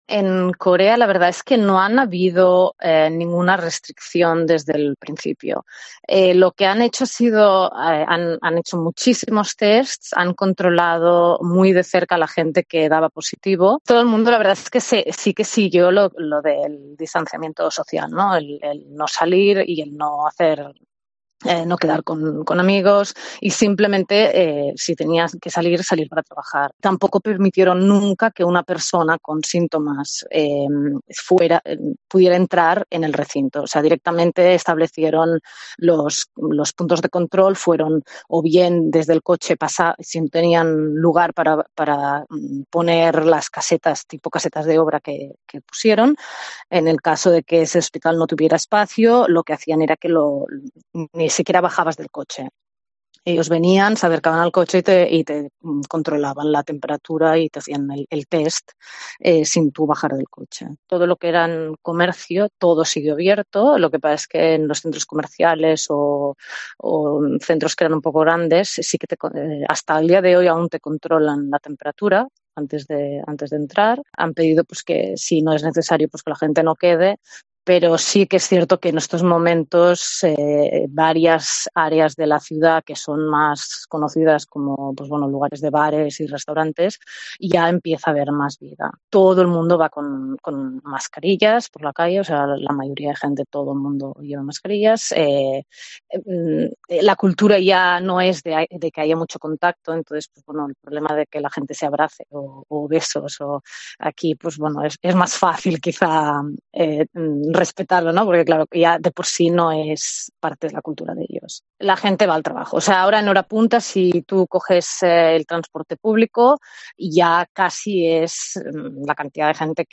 Una arquitecta barcelonesa cuenta a COPE desde Seúl que los surcoreanos ya van a trabajar y que “en los bares empieza a haber más vida”